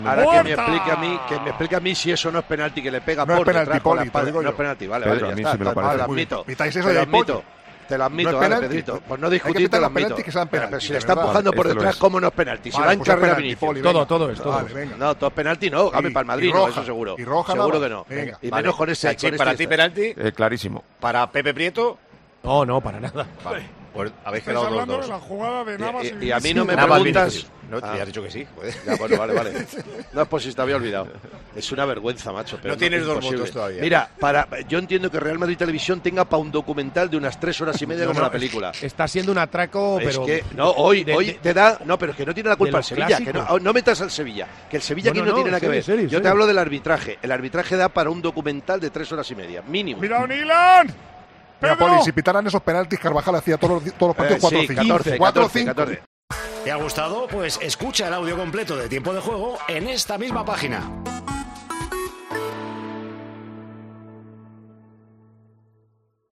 El comentarista de Tiempo de Juego mostró su enfado por varias jugadas polémicas durante el partido que se disputó en el Sánchez Pizjuán.